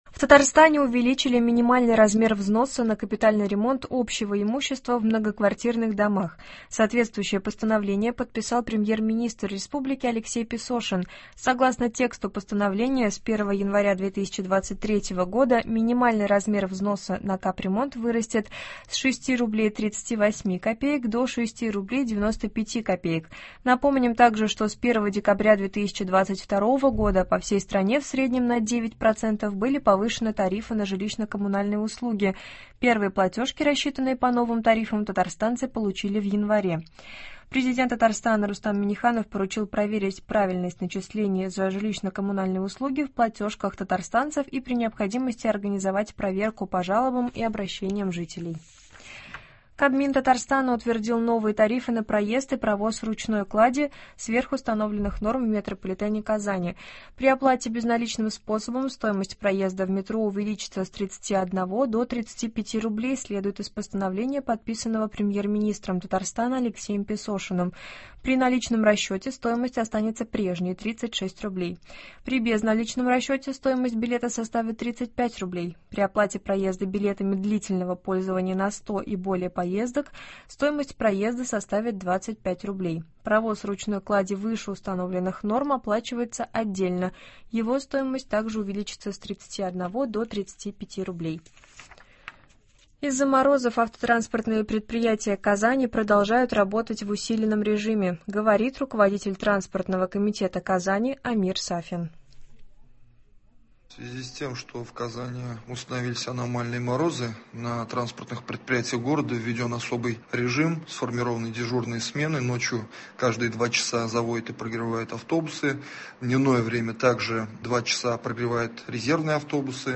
Новости (10.01.23)